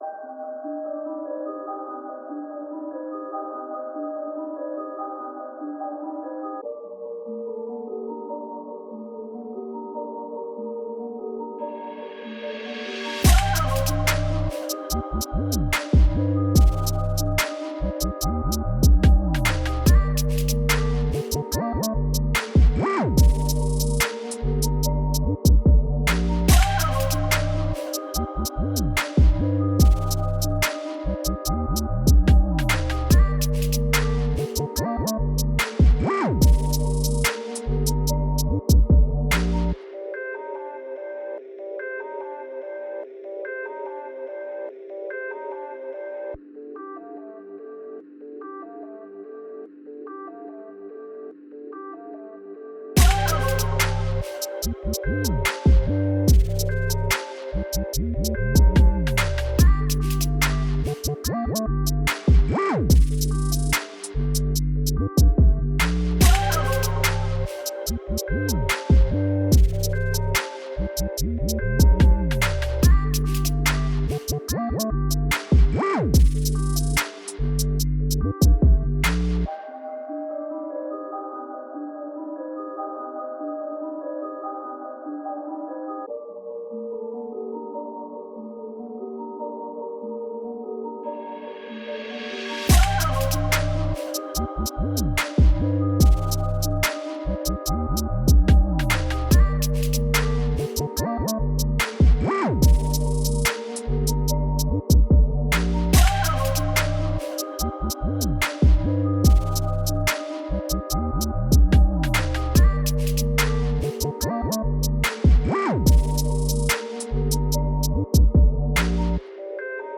Hip Hop Instrumentals